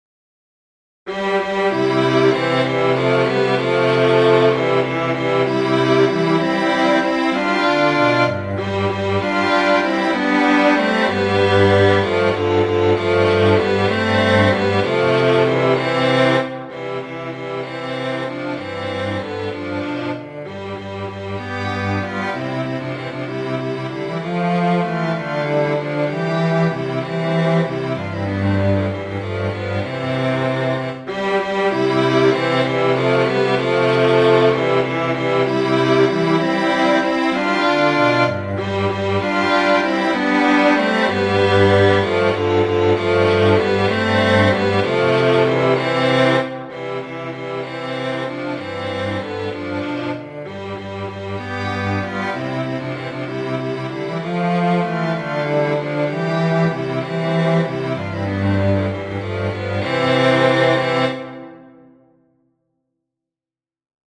Musiche digitali in mp3 tratte dagli spartiti dell'opuscolo